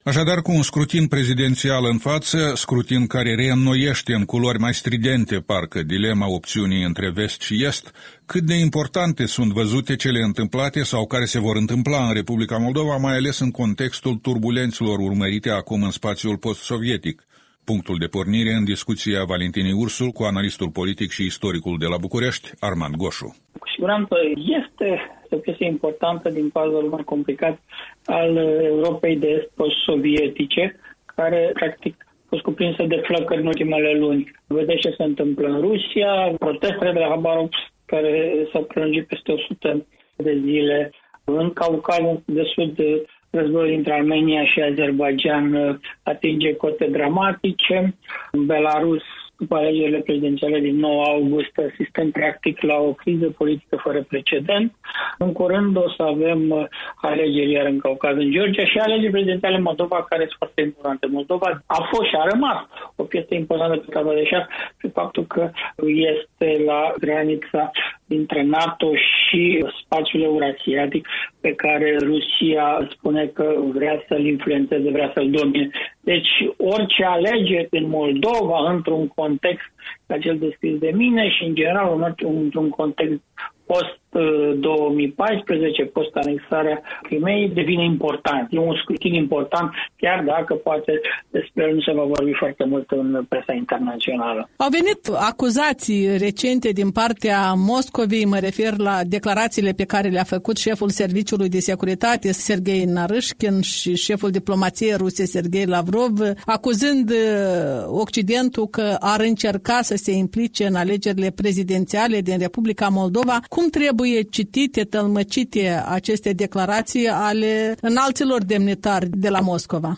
Interviu matinal